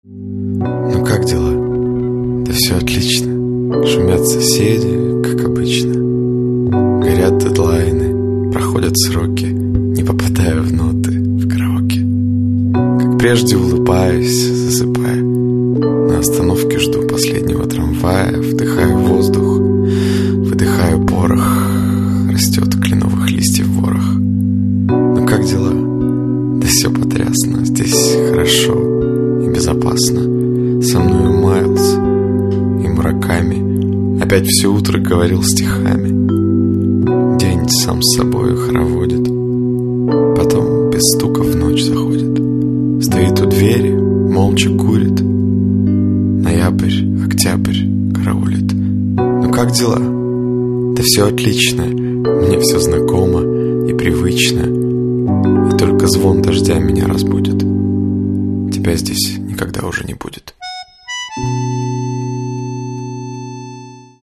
Каталог -> Рок и альтернатива -> Лирический андеграунд